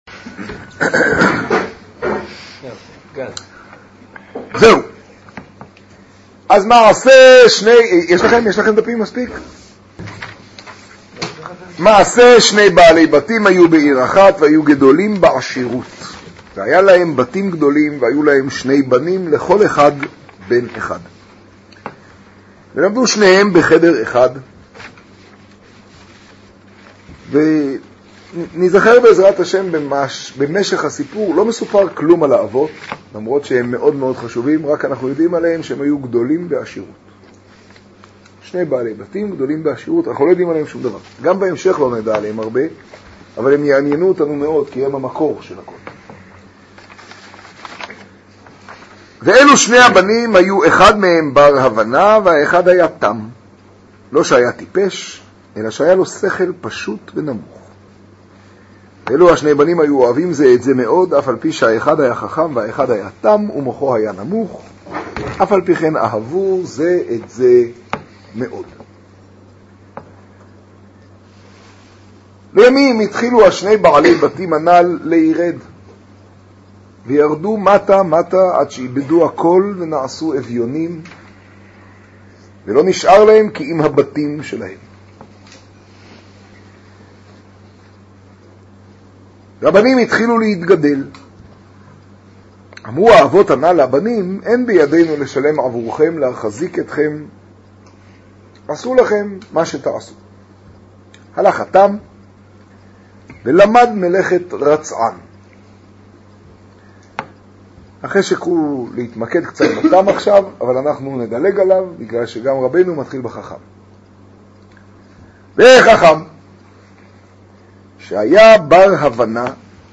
השיעור באור עציון תשעג.
קטגוריה: שיעור, שיעור באור עציון, תוכןתג: מעשה מחכם ותם, סיפורי מעשיות